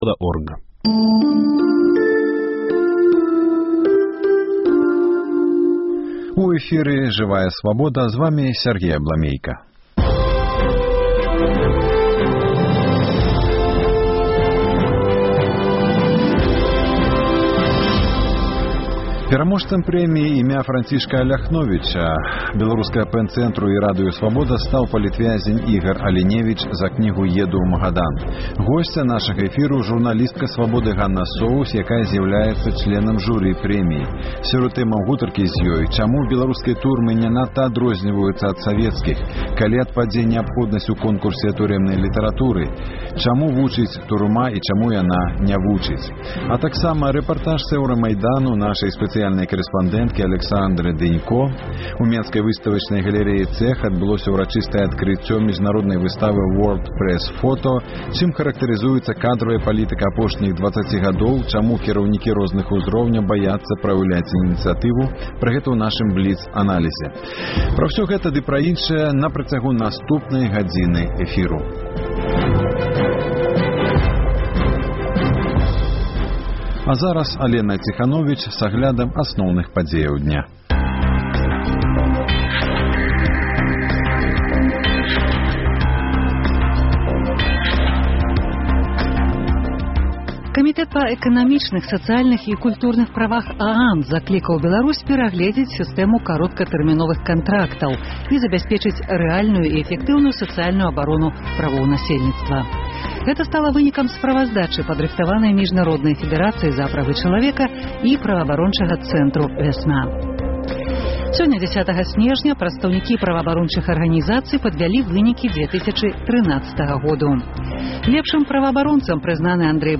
Рэпартаж з Эўрамайдану